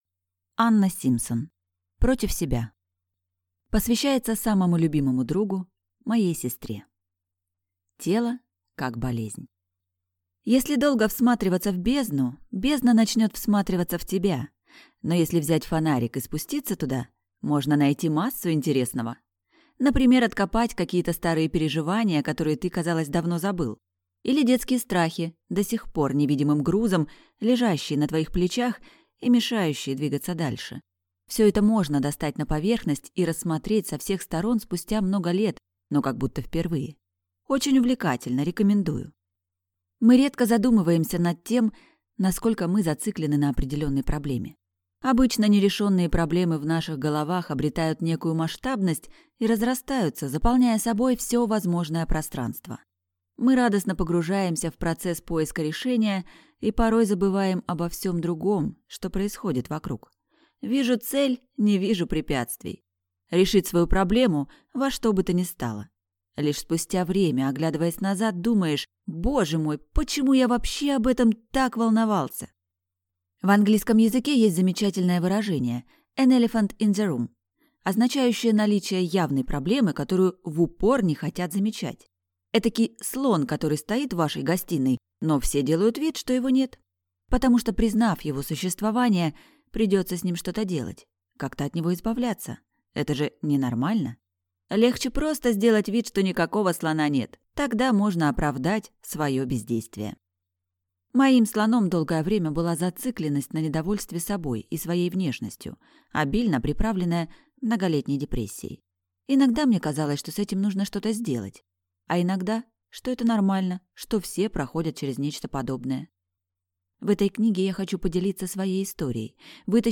Аудиокнига Против себя | Библиотека аудиокниг